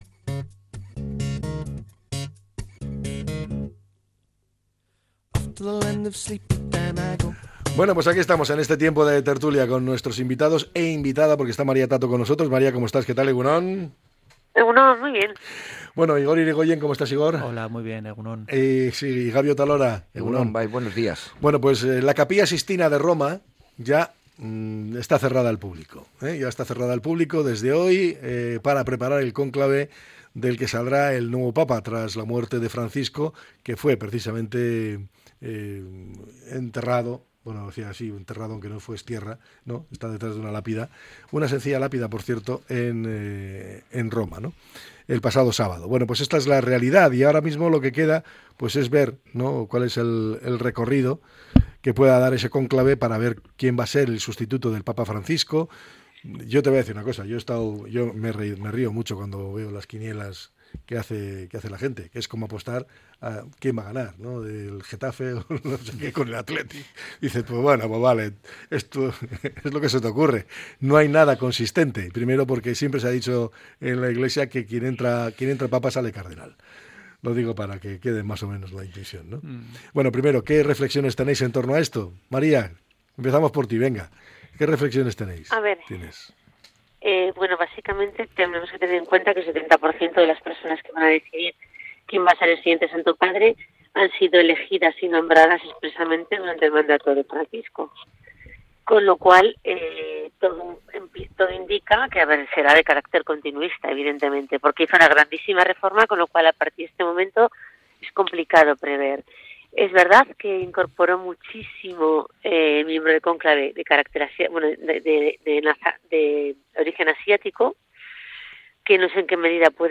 La tertulia 28-04-25.